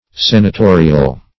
senatorial - definition of senatorial - synonyms, pronunciation, spelling from Free Dictionary
Senatorial \Sen`a*to"ri*al\, a. [F. s['e]natorial, or L.